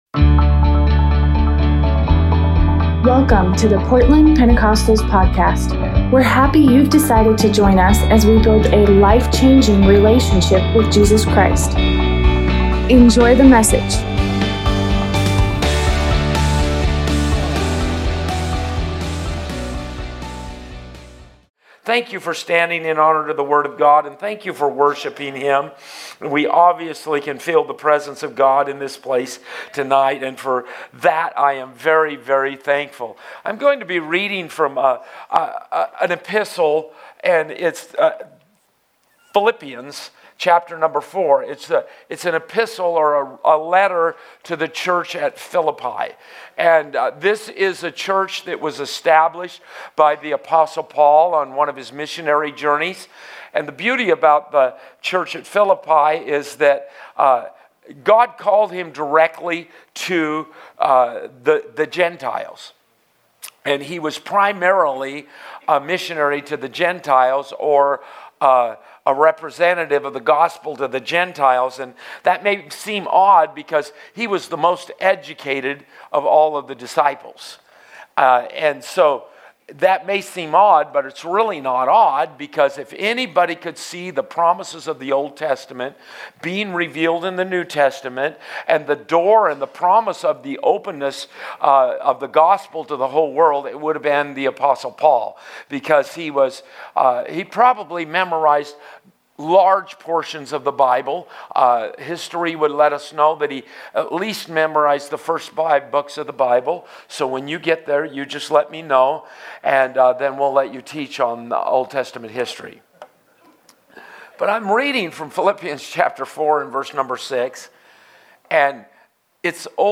Tuesday Bible Study